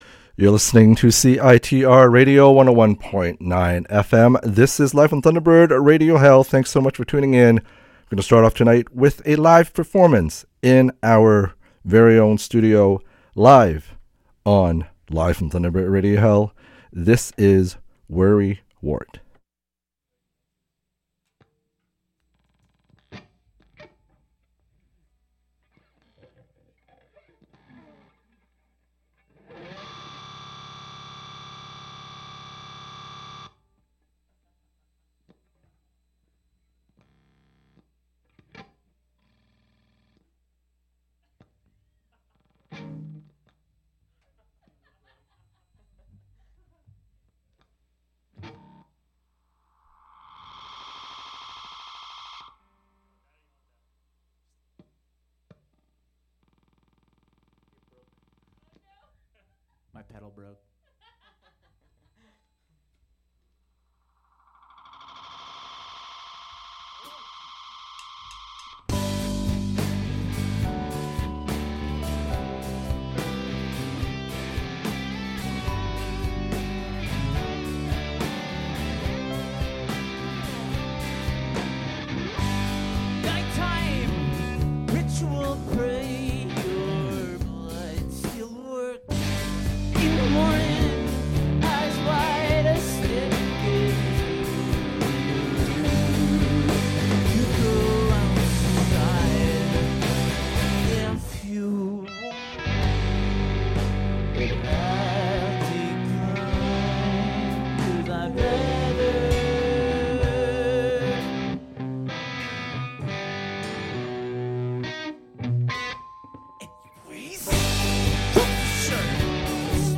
Live in studio performance